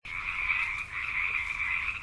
Frogs_01.ogg